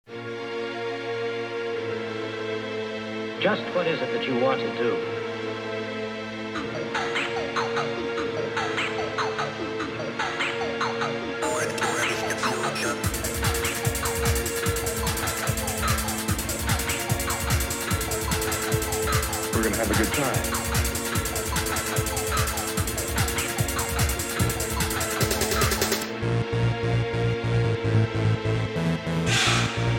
Digital Stereo Techno-Rave Cyber-Delic Audio Sound Tracks